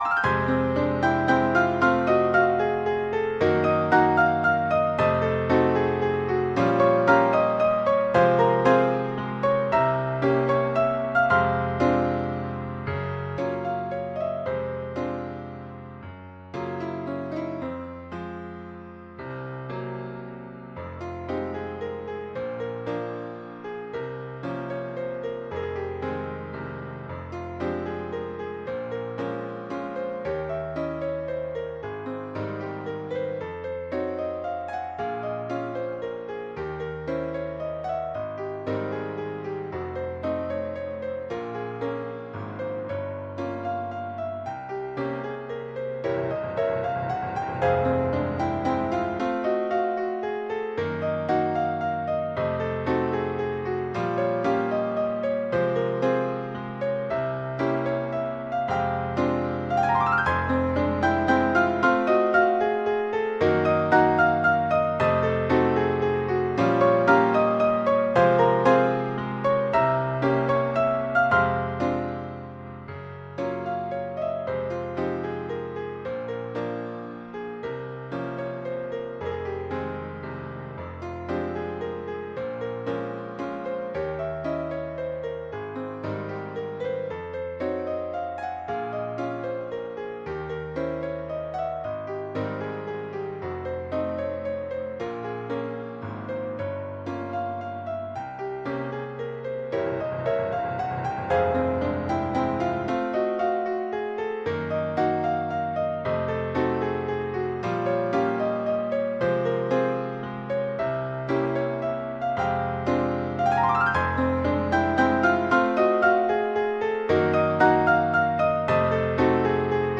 • Теги: Ноты для фортепиано
Ноты для фортепиано.